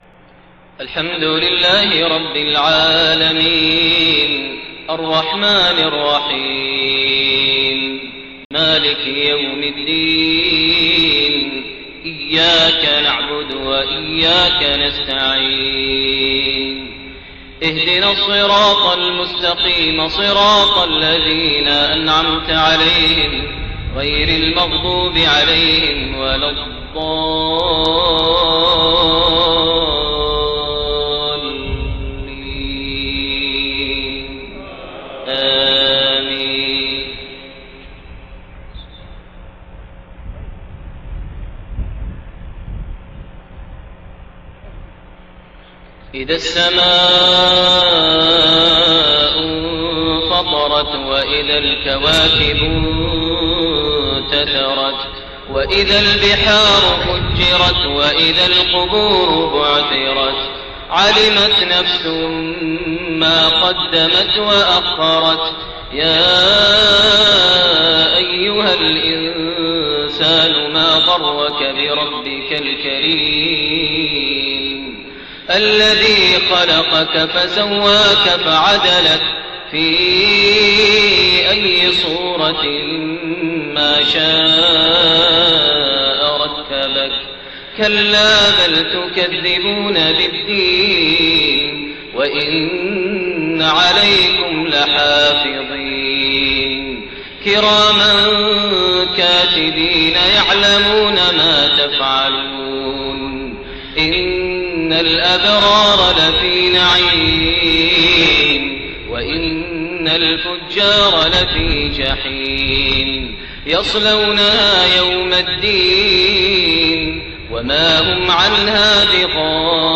Maghrib prayer Surat Al-Infitaar and Al-Qaari'a > 1429 H > Prayers - Maher Almuaiqly Recitations